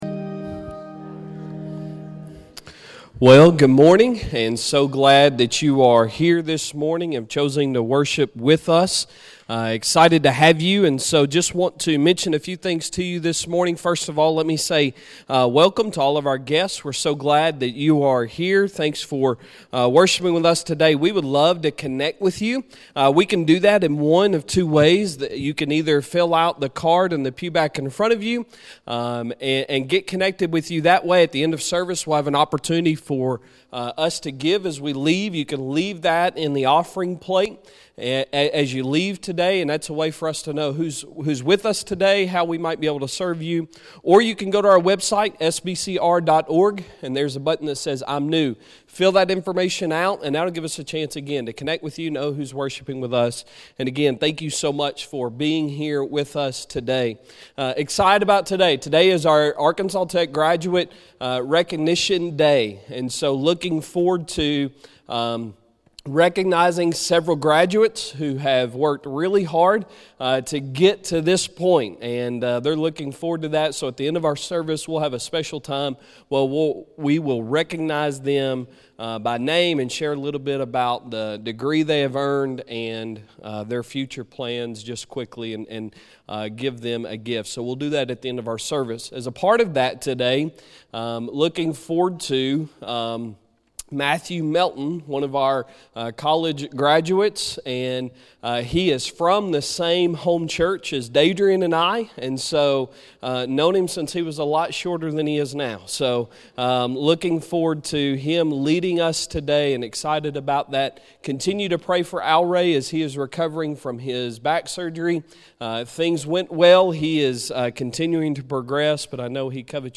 Sunday Sermon May 1, 2022